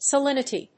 音節sa・lin・i・ty 発音記号・読み方
/seɪlínəṭi(米国英語), səɪlínəṭi(英国英語)/